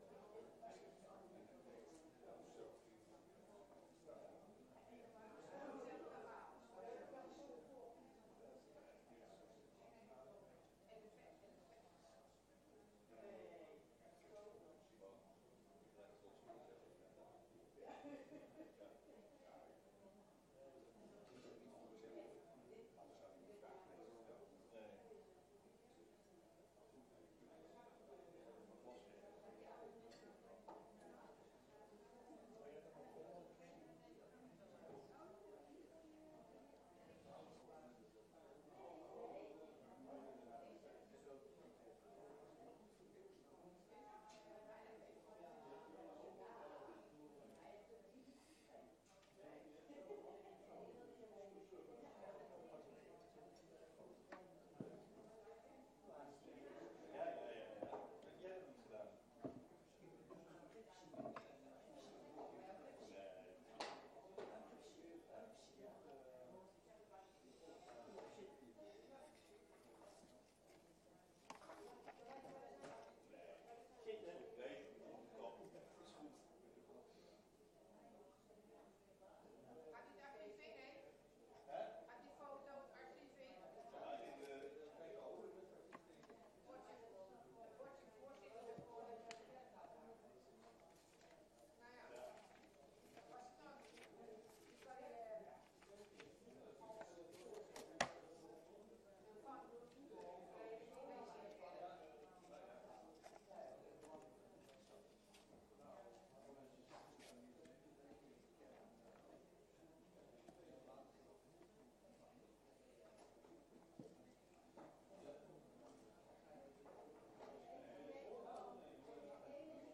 Dit is een fysieke vergadering met inachtneming van de geldende landelijke maatregelen ter voorkoming van de verspreiding van het coronavirus.